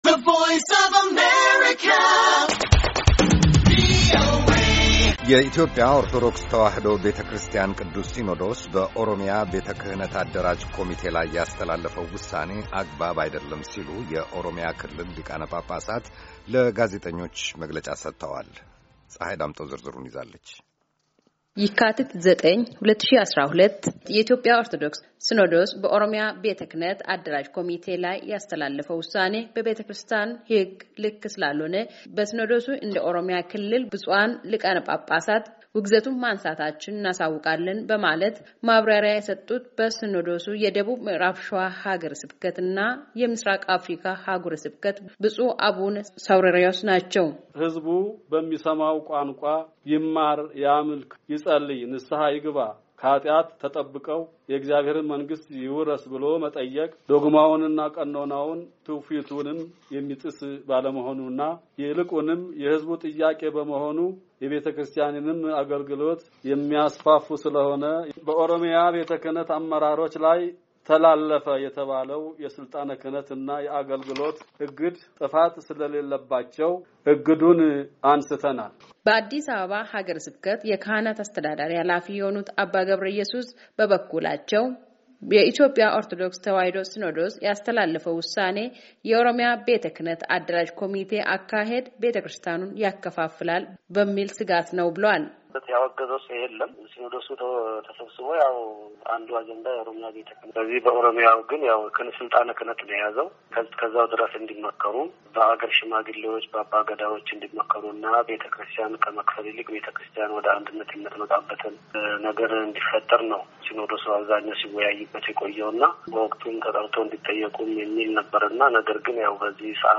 በኦሮምያ ክልል ከሚገኙ የኢትዮጵያ ኦርቶዶክስ ቤተክርስቲያን አገረ ስብከቶች የመጡ አንዳንድ ሊቃነ ጳጳሳት የሰጡት መግለጫ